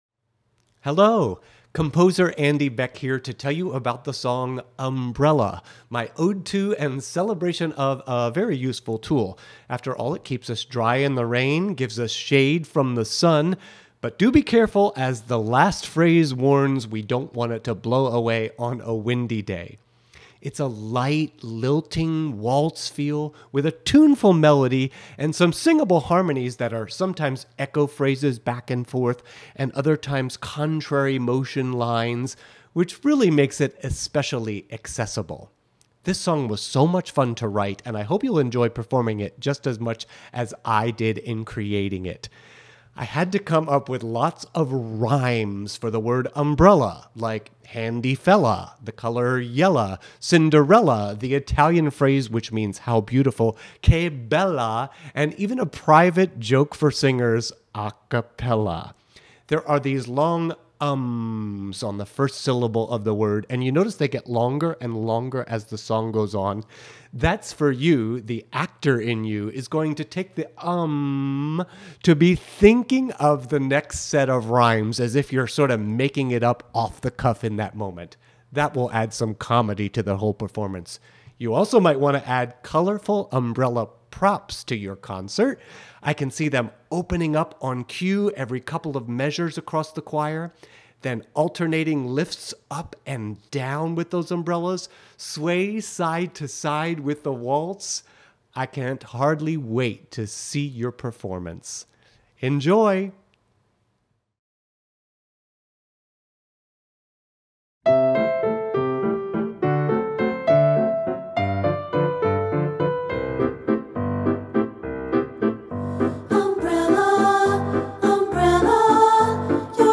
lilting waltz tempo